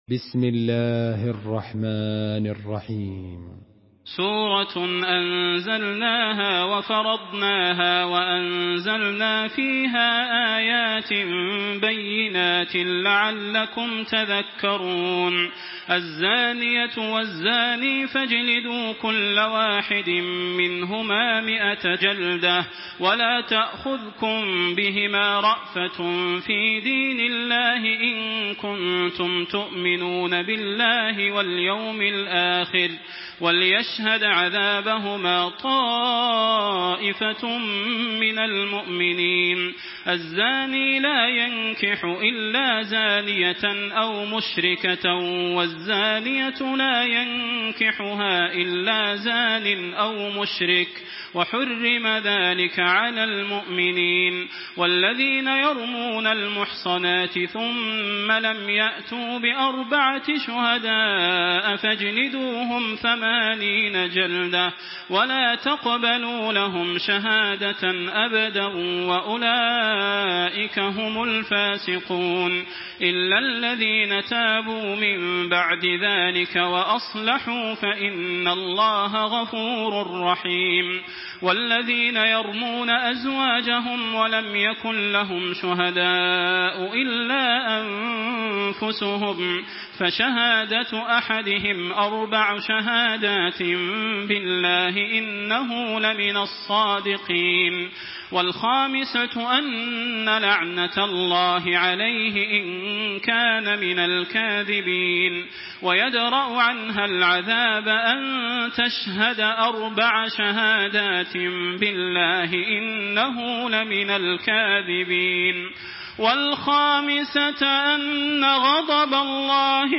Surah An-Nur MP3 in the Voice of Makkah Taraweeh 1426 in Hafs Narration
Murattal Hafs An Asim